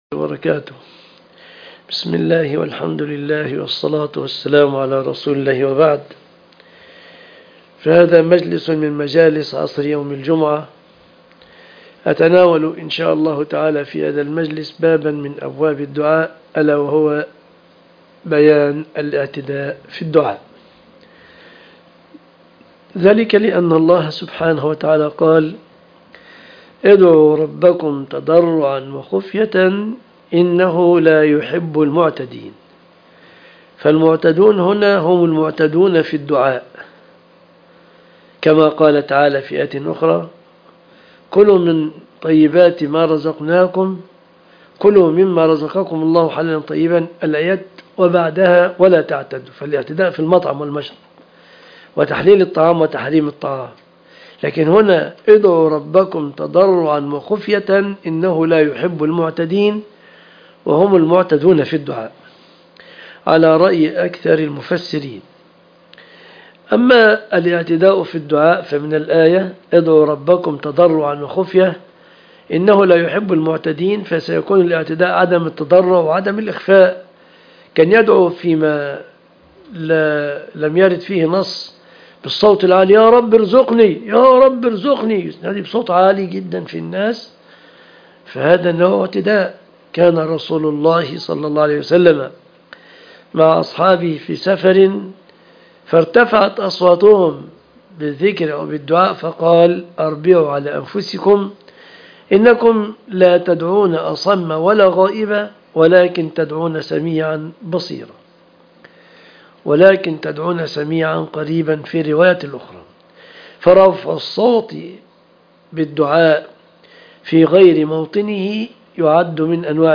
درس عصر يوم الجمعة